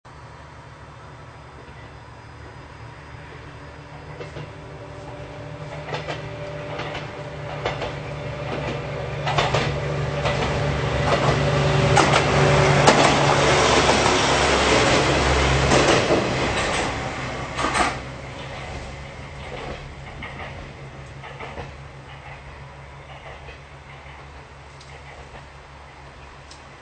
背後の国道３５５号線の車もうまい按配に途切れて、余計な音も入らなかった。
ちなみに通過後のキハ４３２の音がこもるのは、国道３５５号線をくぐるためである。
キハ４３２走行音（ＭＰ３）